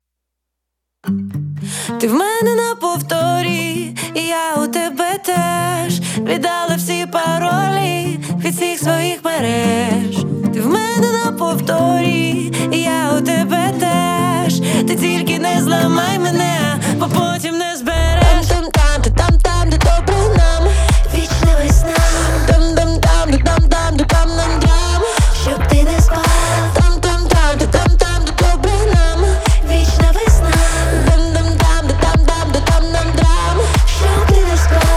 Жанр: Поп / Украинский рок / Украинские